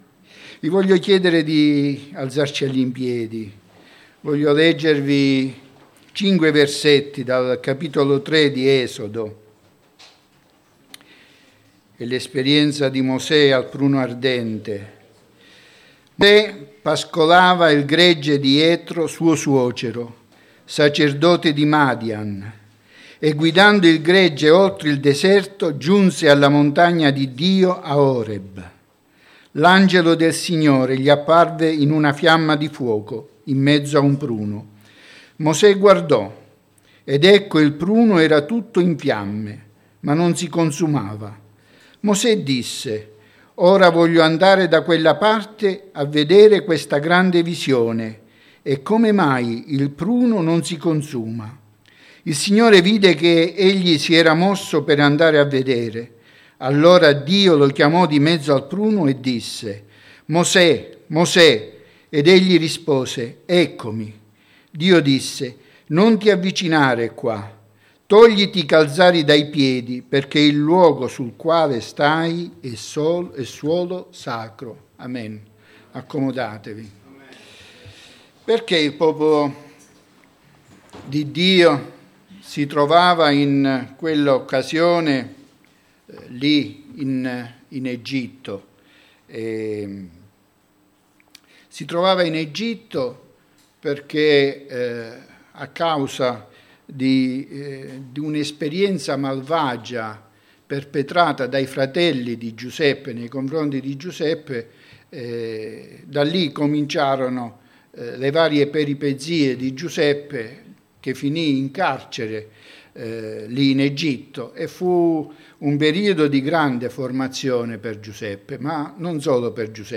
Predicatore